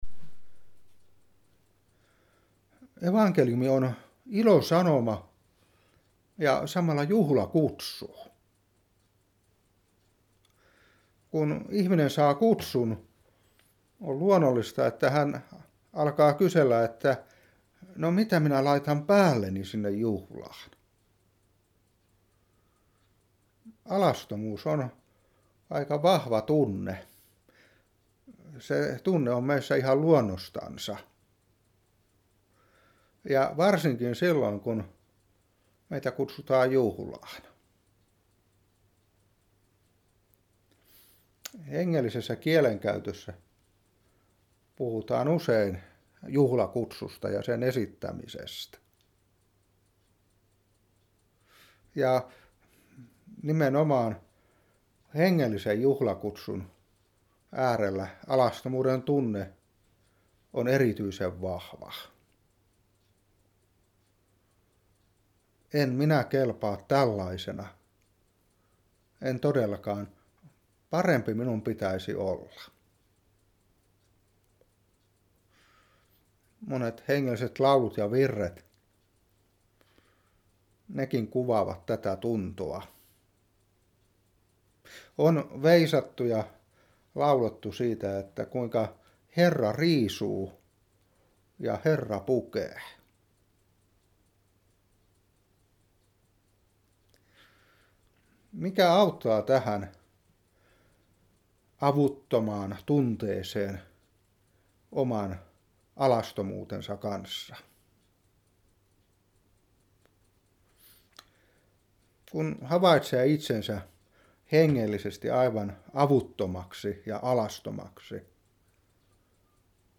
Seurapuhe 2016-1.